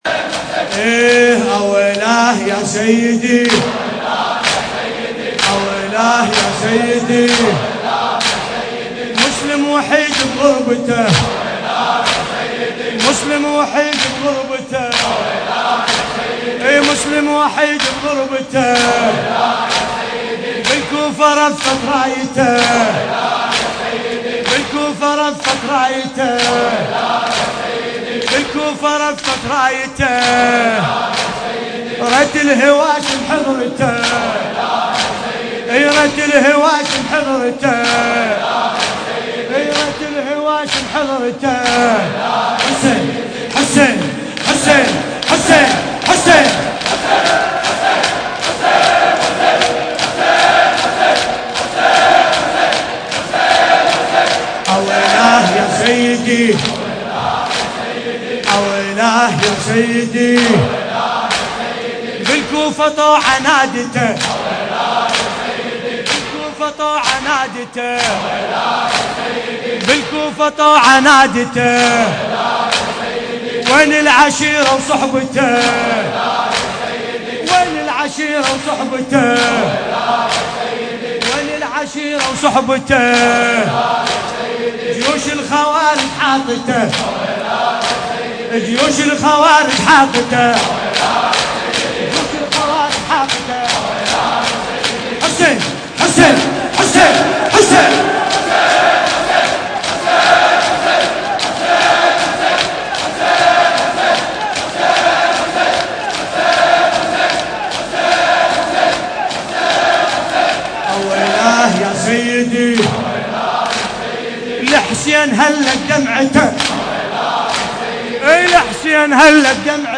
القارئ: باسم الكربلائي التاريخ: الليلة الخامسة من شهر محرم الحرام 1424 هـ - الكويت.